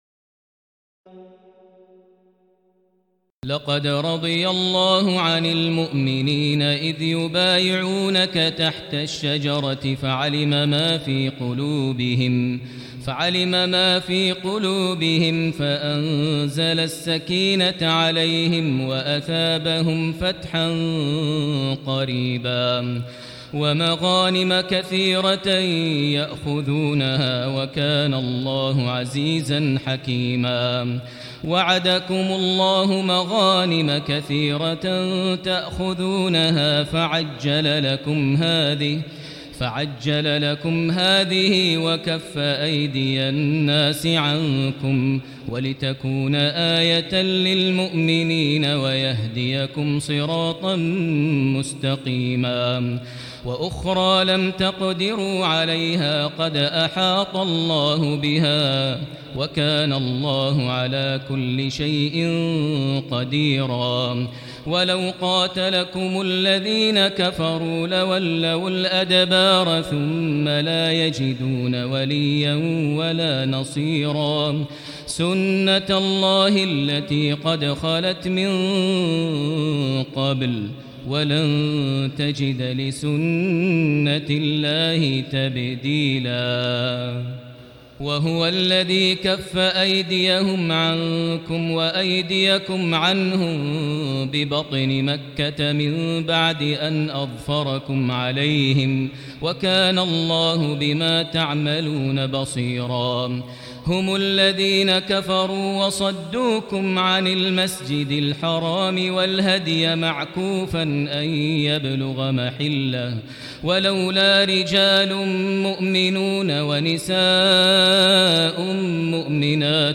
تراويح ليلة 25 رمضان 1439هـ من سور الفتح (18-29) الحجرات و ق و الذاريات (1-37) Taraweeh 25 st night Ramadan 1439H from Surah Al-Fath and Al-Hujuraat and Qaaf and Adh-Dhaariyat > تراويح الحرم المكي عام 1439 🕋 > التراويح - تلاوات الحرمين